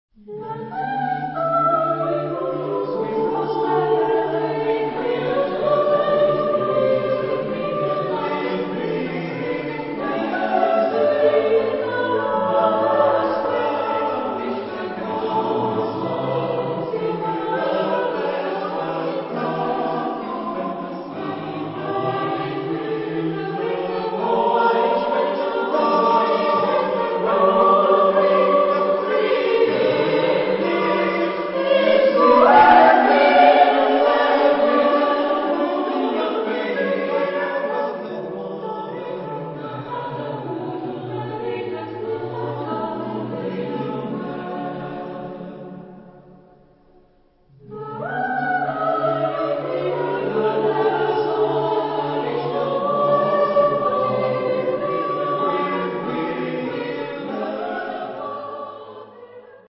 for unaccompanied voices
Epoque: 20th century  (1970-1979)
Genre-Style-Form: Secular ; Choir
Type of Choir: SSATB  (5 mixed voices )
Tonality: D major